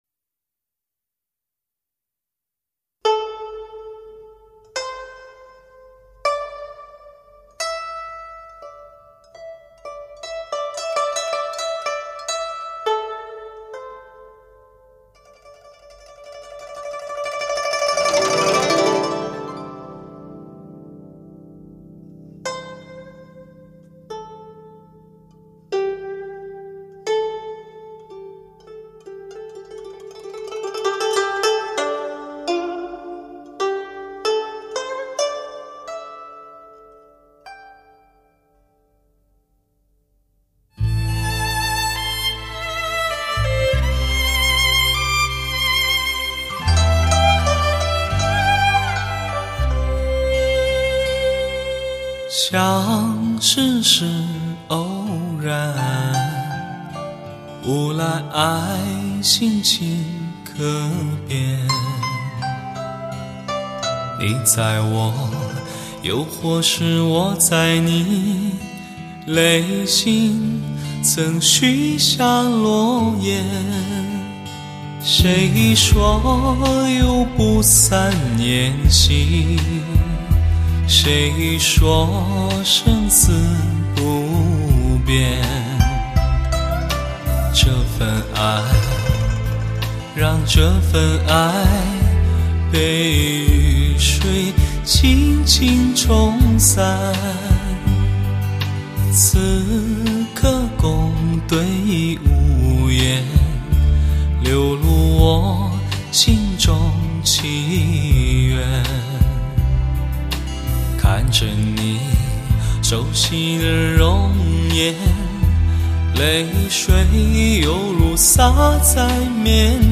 6位发烧极品的男声，16首爱来释手的极致曲目
专为名车量身定制的人声发烧利器，车厢空间感与飞跃平衡度最强震撼！
临场般360°全方位环绕音效，超高解析度最完呈现